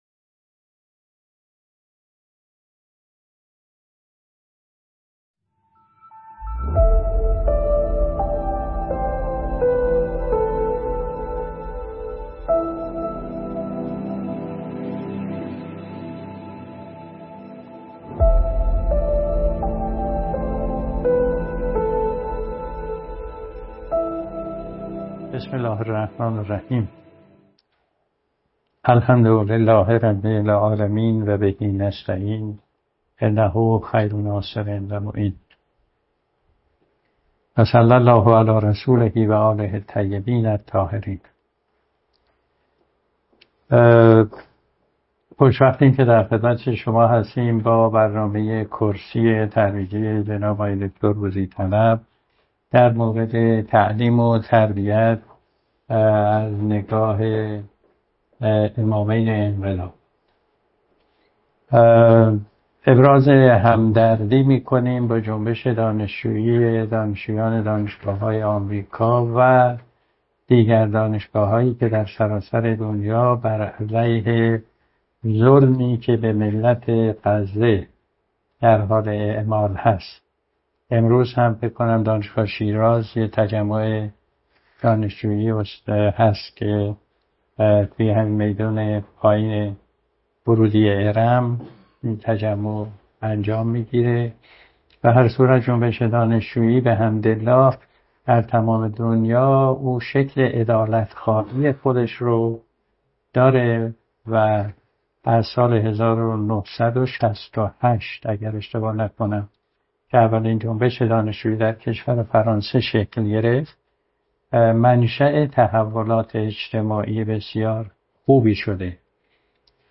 در محل سالن پژوهشکده تحول در علوم انسانی دانشگاه شیراز برگزار گردید.